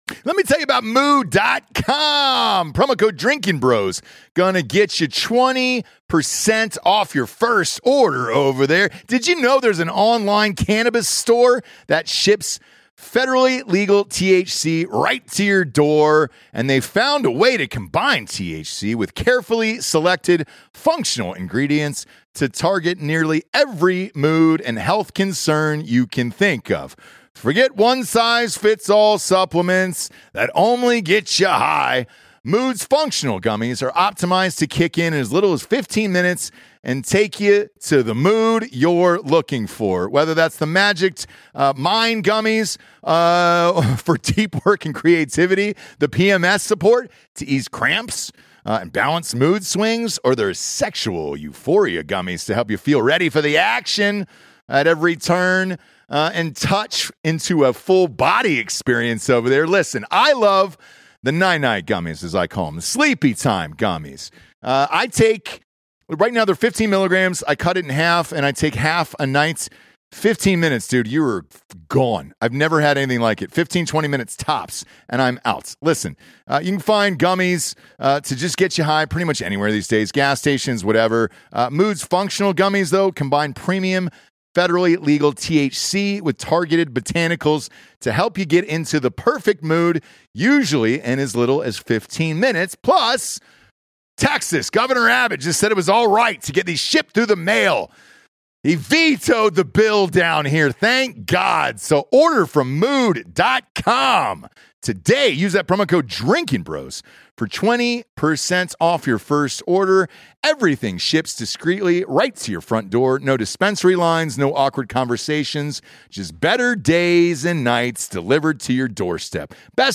The show gets interrupted live on air by something we've never experienced.